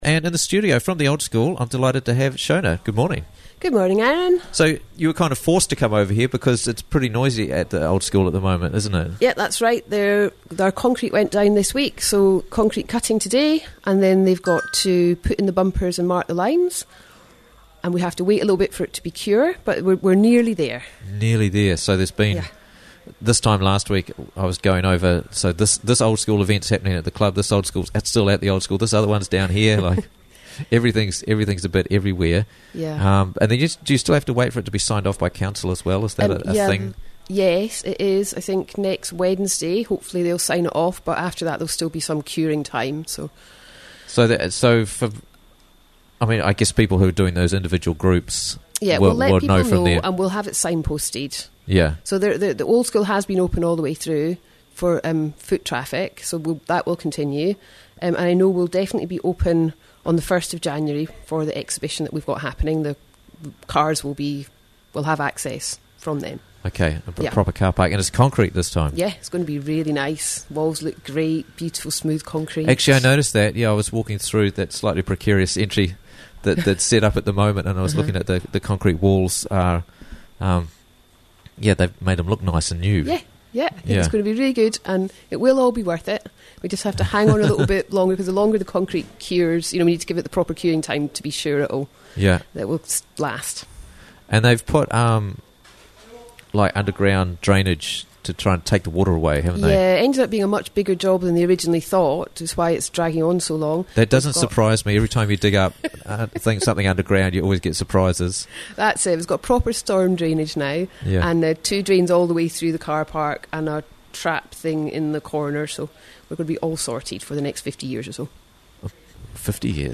joins us in studio because it's too noisy at the Old School while they still work on the carpark